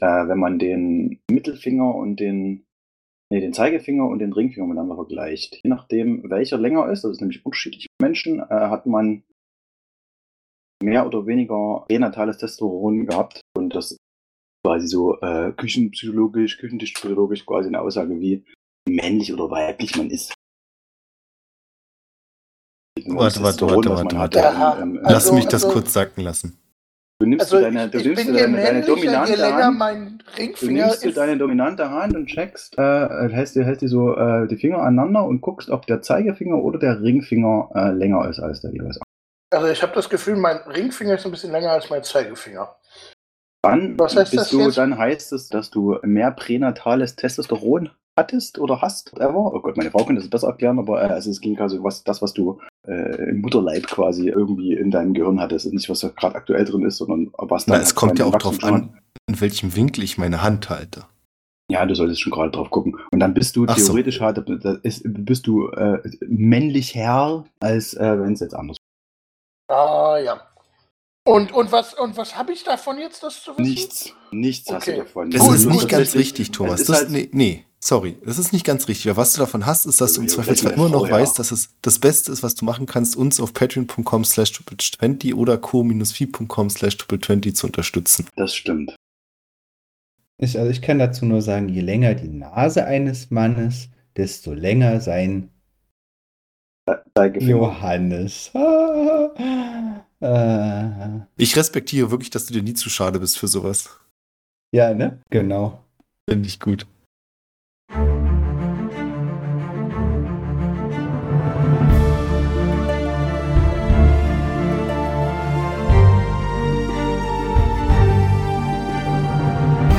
Actual Play
Audio Drama